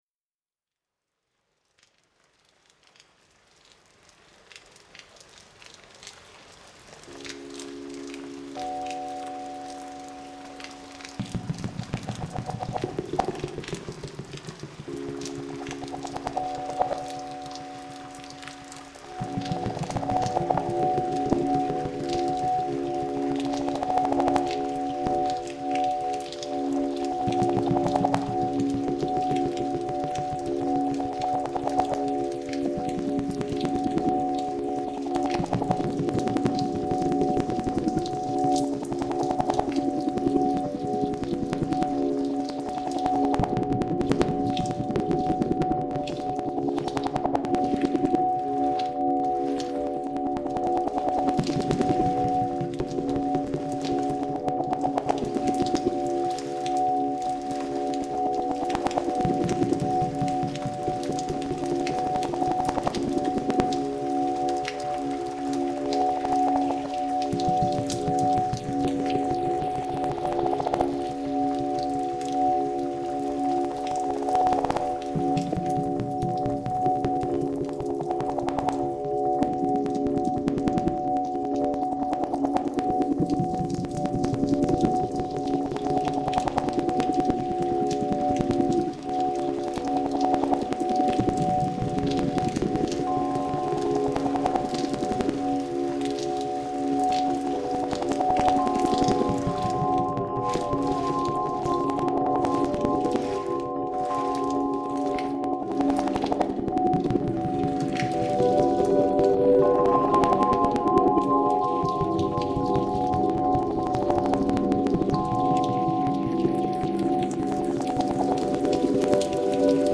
When this happened, it was just a cluster of collateral effects; they were recorded, by chance.